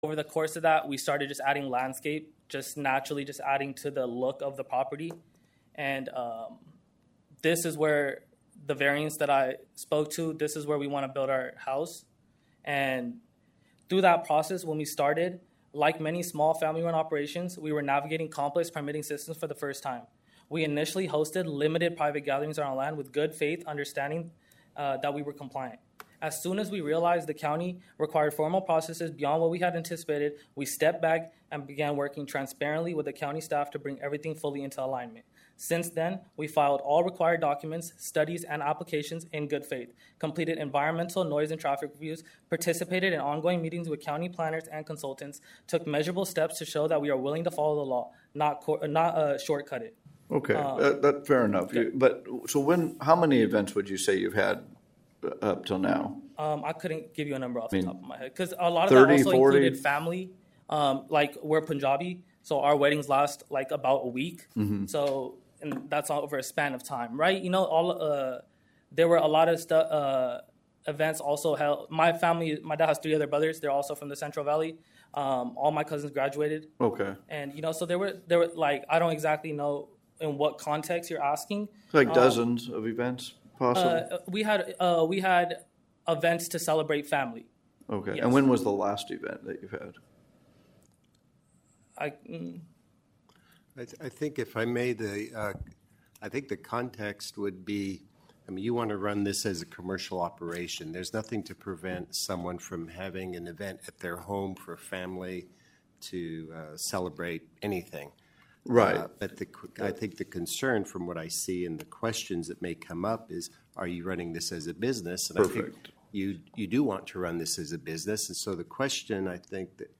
June 26, 2025 Fresno County Planning Commission Hearing